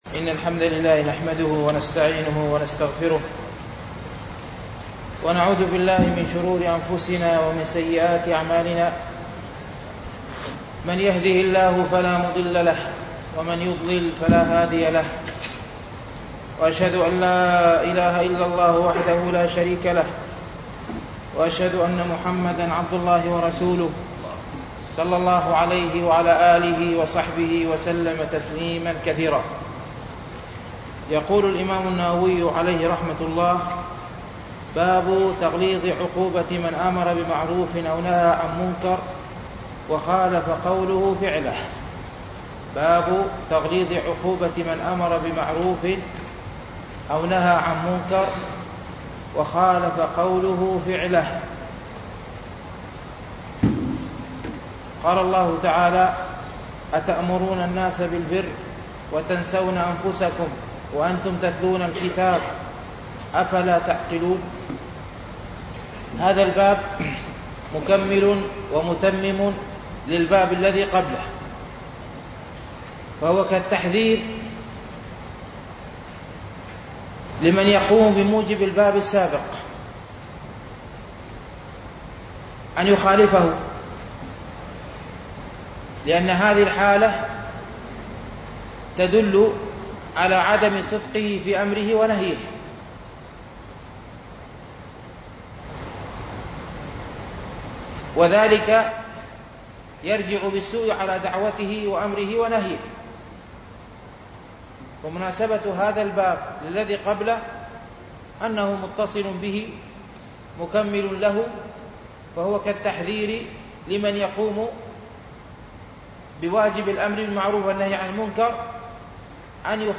شرح رياض الصالحين ـ الدرس الثامن والأربعون